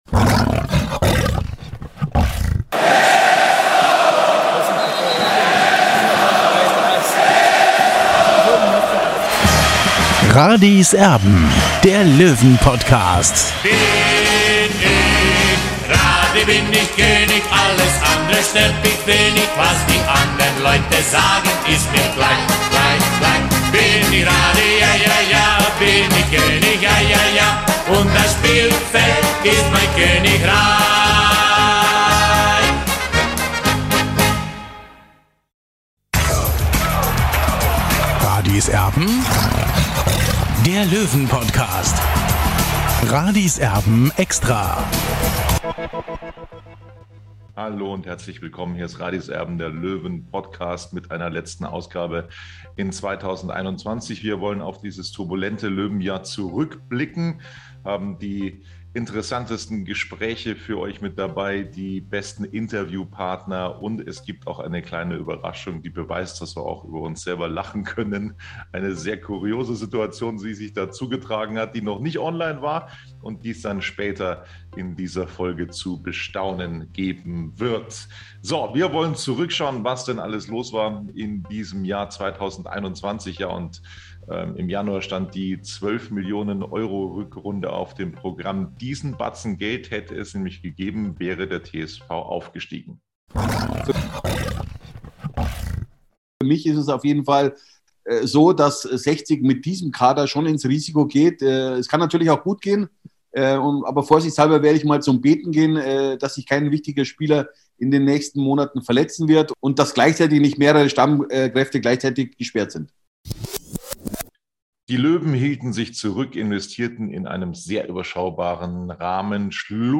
Ehemalige Helden, aktuelle Spieler und viele andere Hauptdarsteller aus dem Löwenrevier kommen in diesem Format zu Wort und erzählen – ob im Löwenstüberl, im Wohnzimmer oder im Stadion – ihre ganz persönlichen Geschichten.